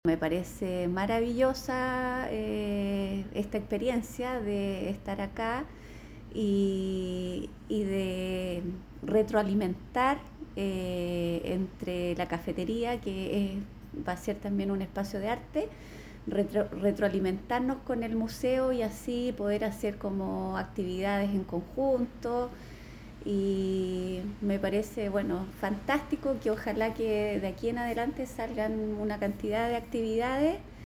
Inauguración de Cafetería Rapelli